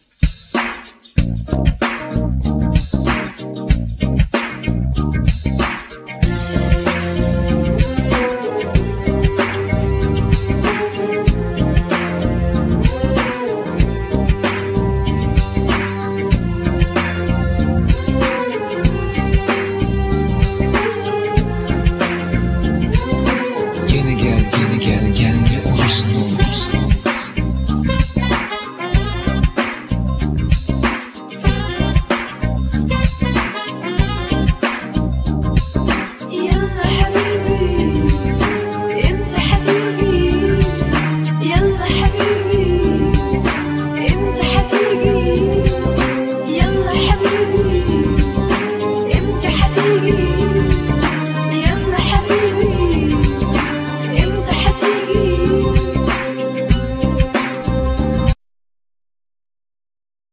Bass,Guitar
Percussion
Vocals
Oud
Saz